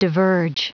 Prononciation du mot diverge en anglais (fichier audio)
Prononciation du mot : diverge